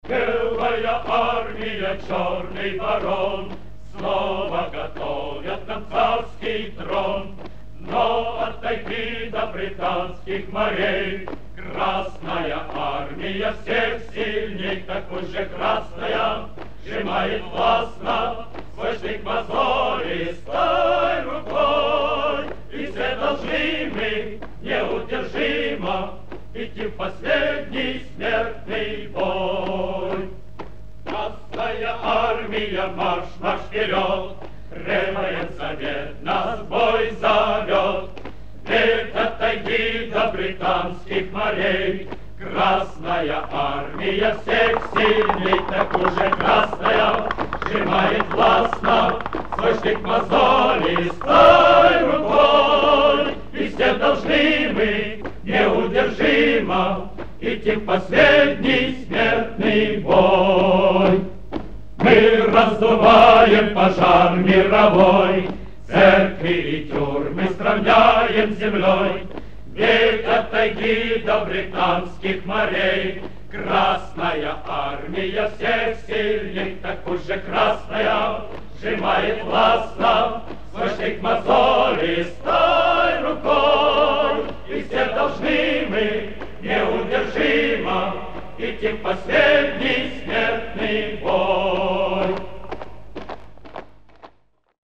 Вариант в исполнении мужского хора